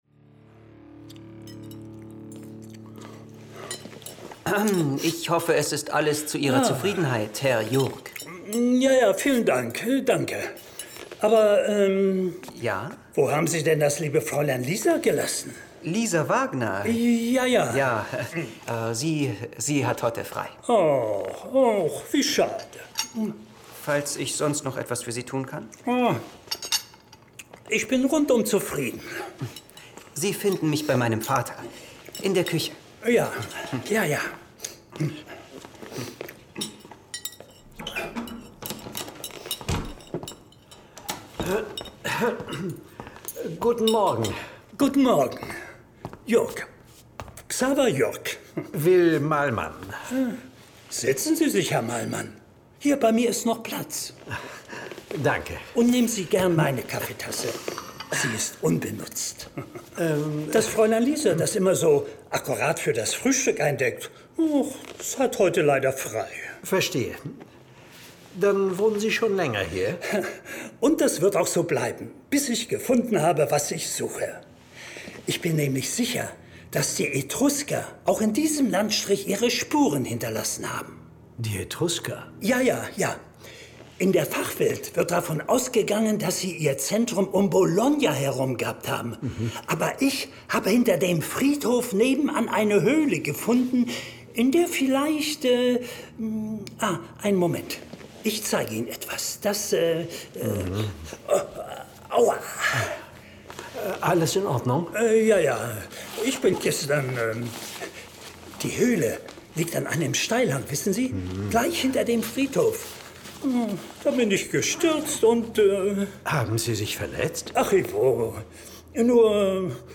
John Sinclair Classics - Folge 40 Der Albtraum-Friedhof. Hörspiel.